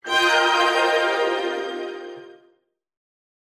Largo [0-10] - - feerique - jingle - transition - virgule